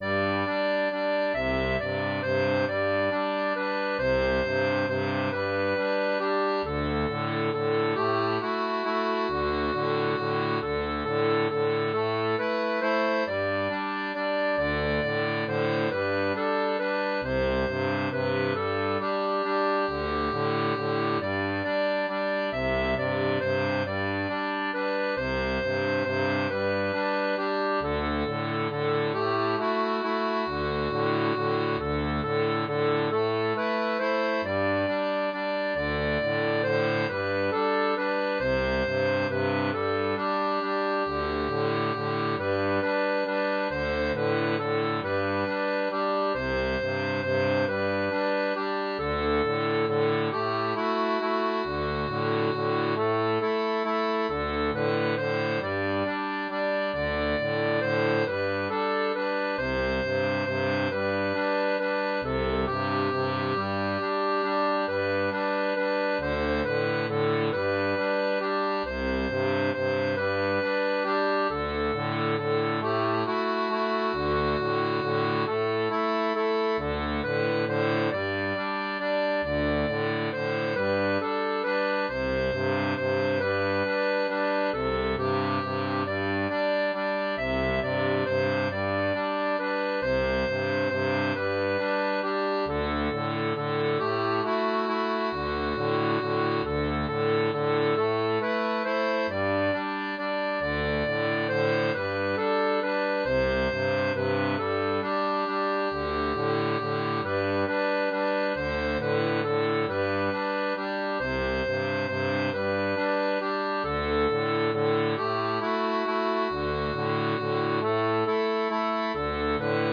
Folk et Traditionnel